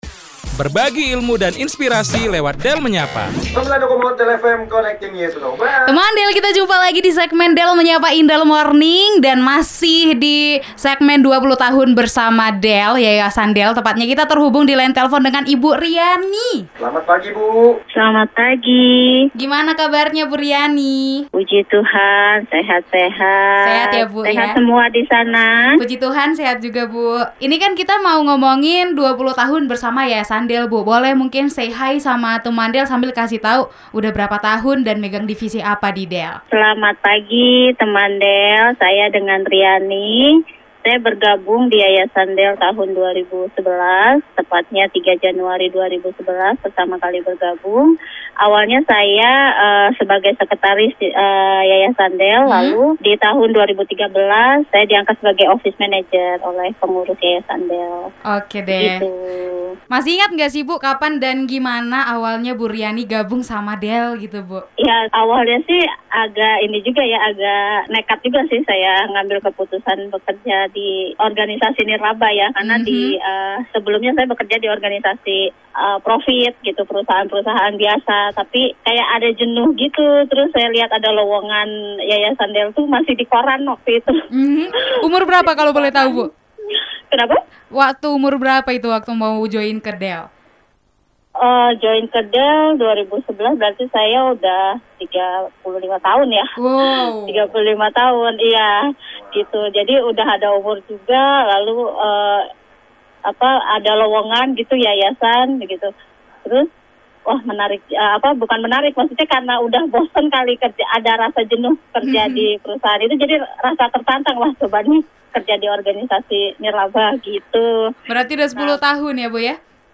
Teman del, tokoh Del berikutnya yang akan diinterview di Del Menyapa